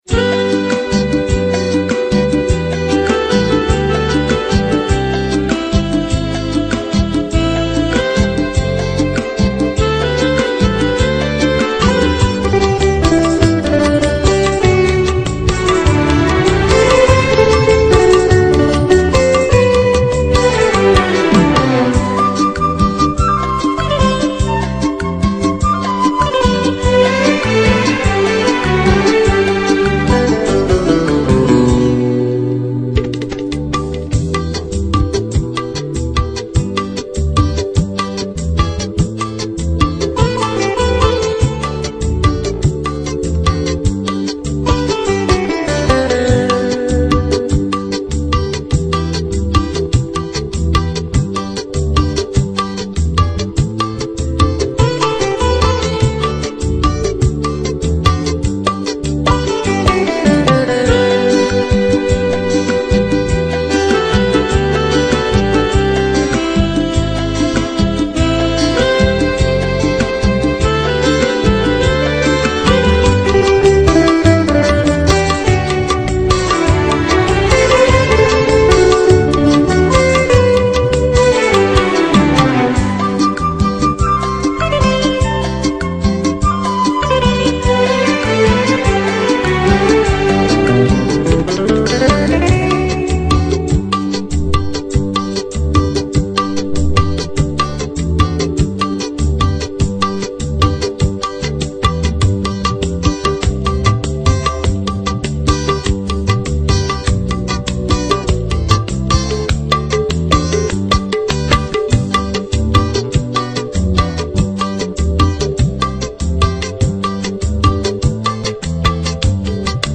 No Voice Karaoke Track Mp3 Download